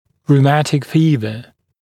[ruː’mætɪk ‘fiːvə][ру:’мэтик ‘фи:вэ]ревматизм